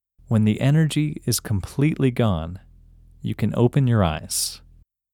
OUT – English Male 33